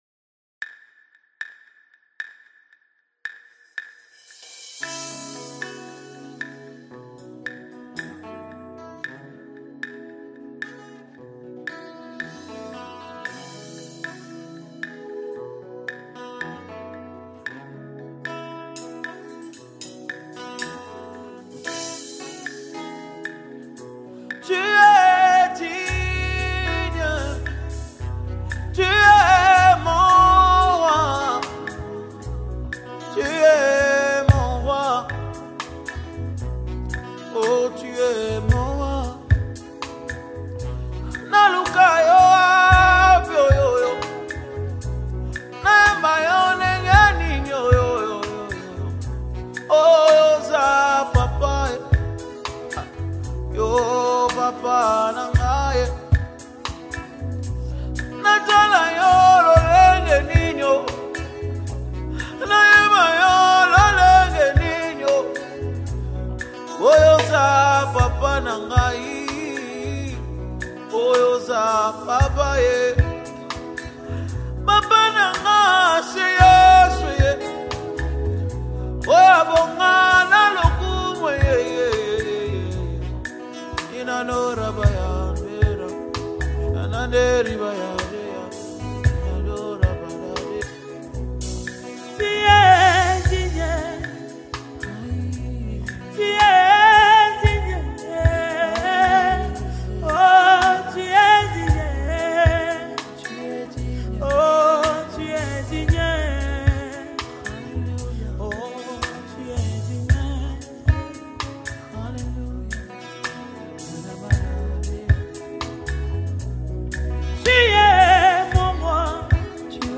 DEEP WORSHIP ANTHEM